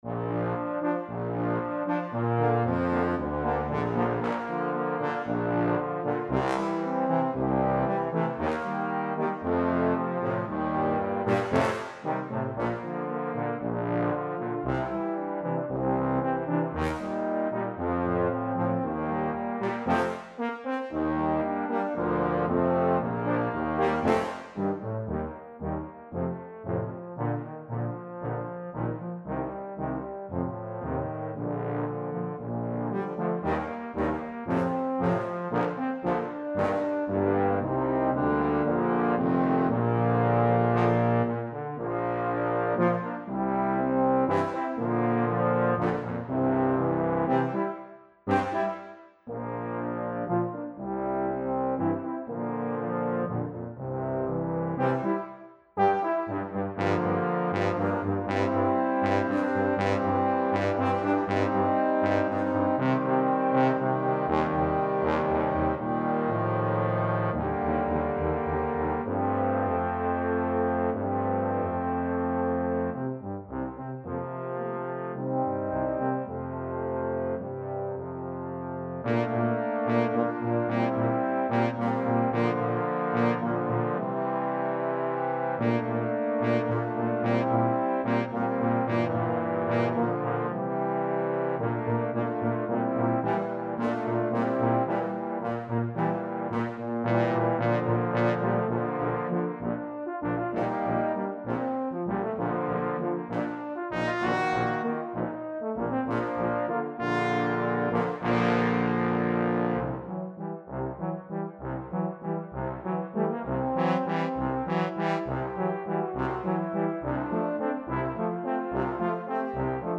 Low Brass Ensemble
Instruments: Euphonium, Tuba, Trombone, Bass Trombone
digital instruments.